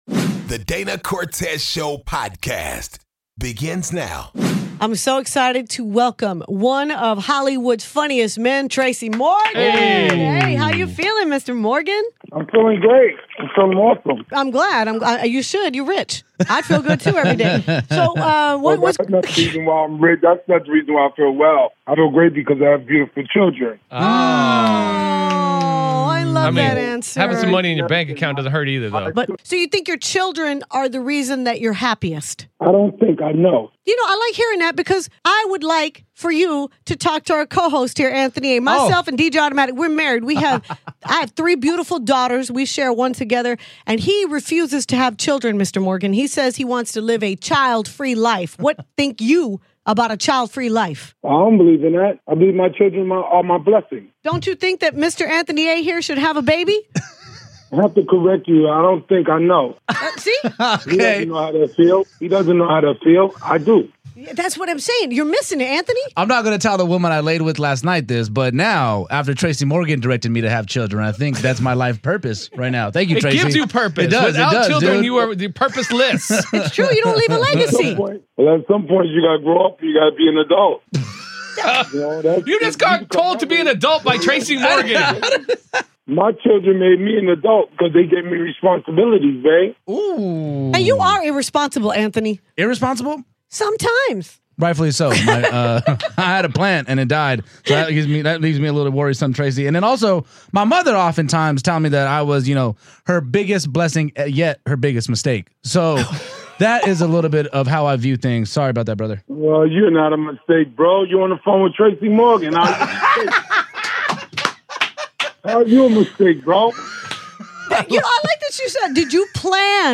DCS Interviews Tracy Morgan